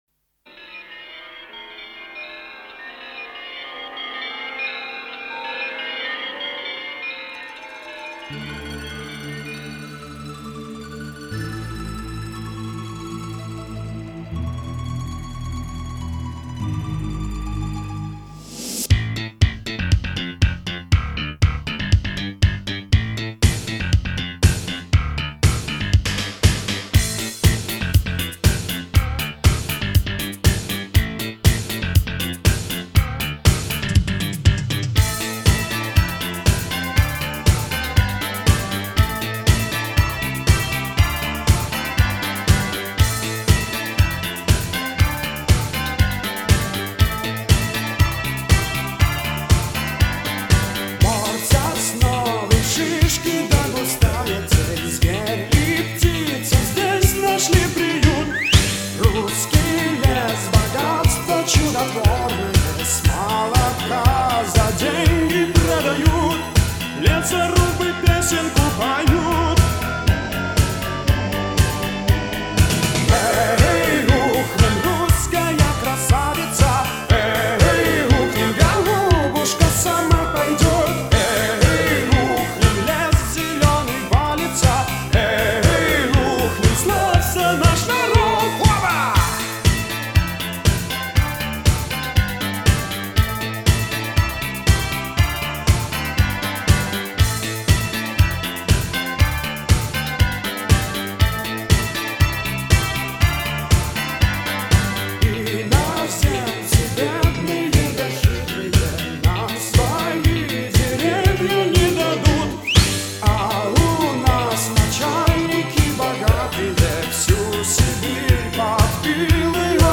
Музыкальный жанр: поп, ретро (диско 80-х)